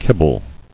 [kib·ble]